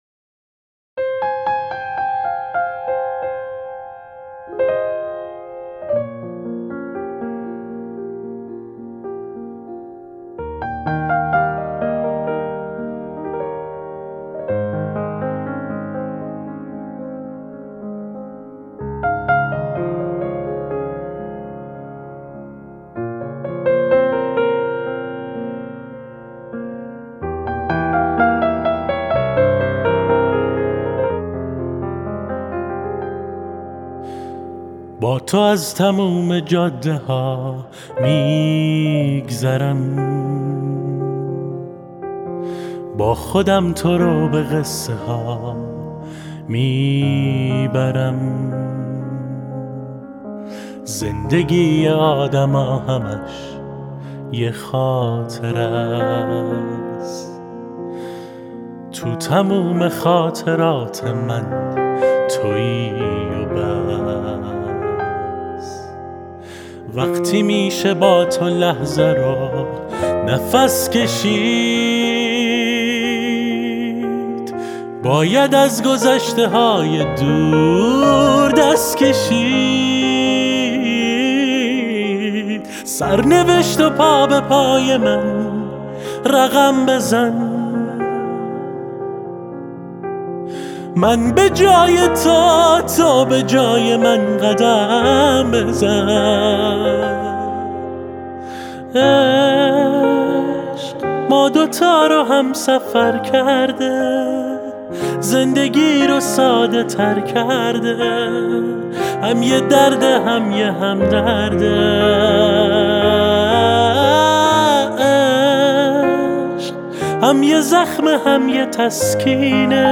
ساکسوفون
پیانو